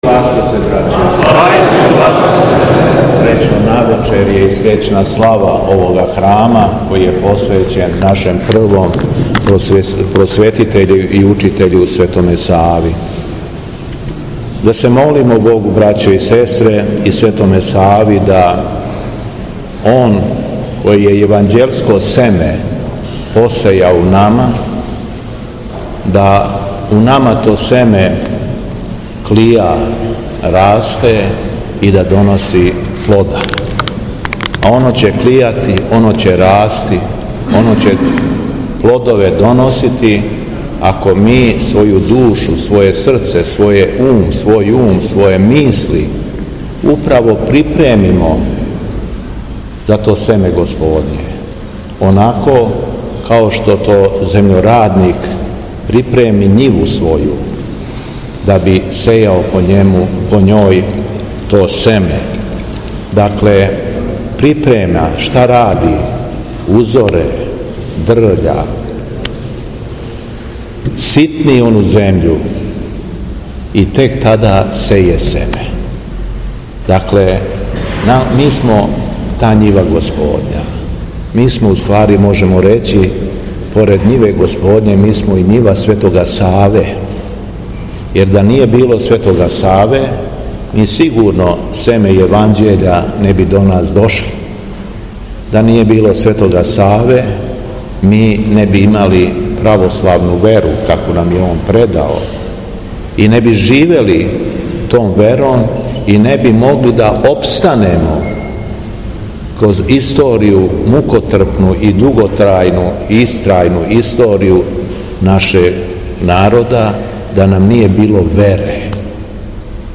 По одслуженом свечаном славском бденију, Преосвећени се обратио верном народу:
Беседа Његовог Преосвештенства Епископа шумадијског г. Јована